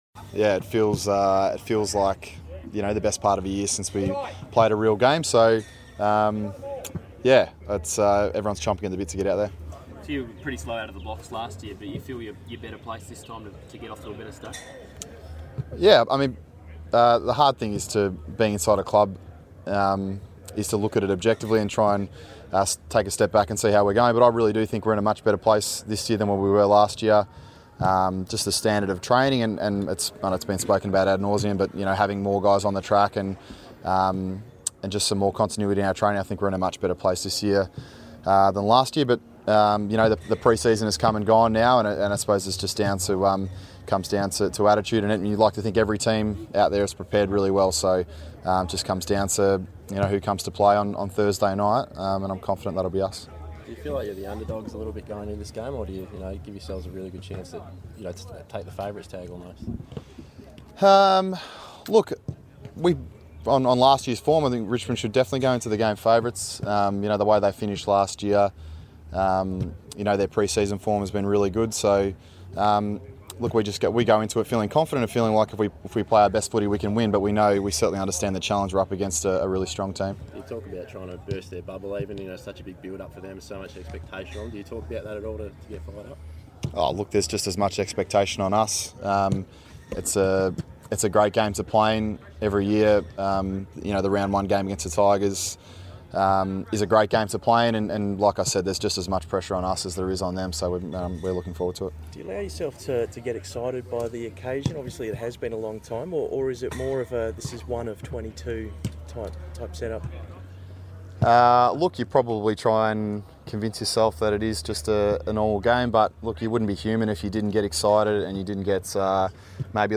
Blues veteran Andrew Carrazzo spoke to the media on Monday morning ahead of Thursday night's Round 1 clash against Richmond.